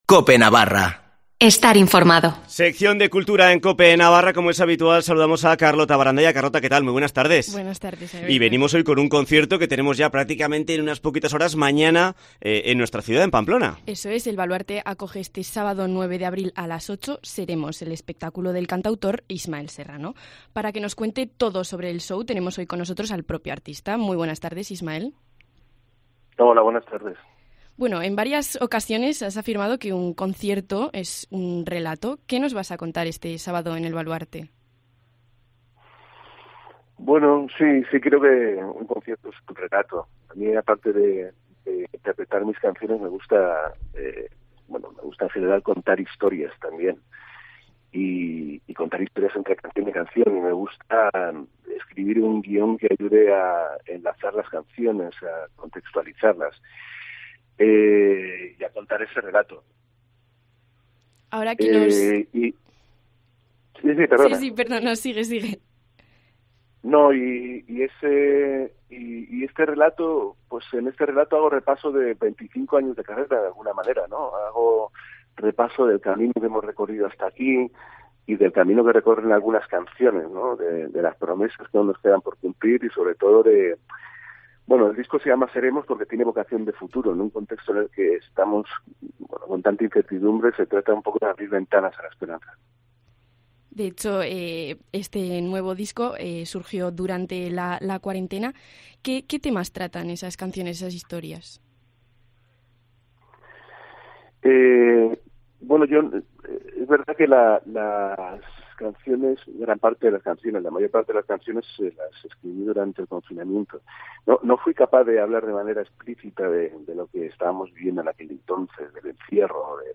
Entrevista a Ismael Serrano